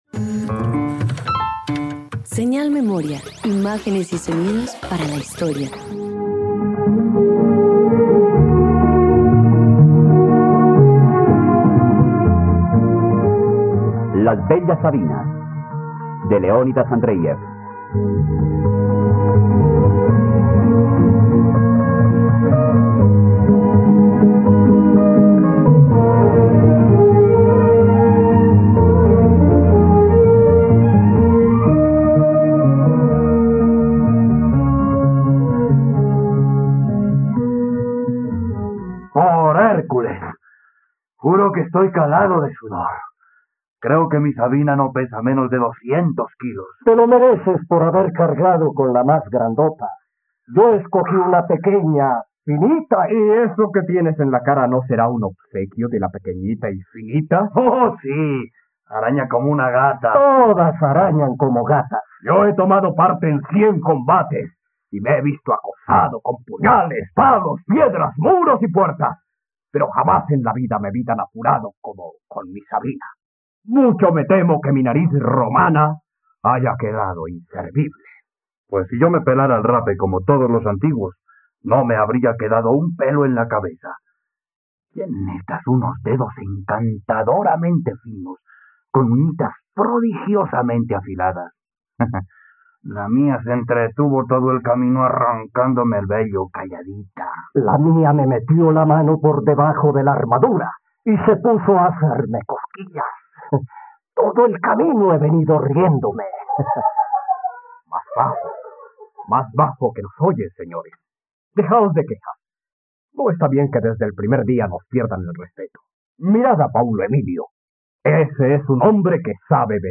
..Radioteatro. Escucha la adaptación radiofónica de “Las bellas Sabines” del escritor y dramaturgo ruso Leónidas Andréiev, disponible en la plataforma de streaming RTVCPlay.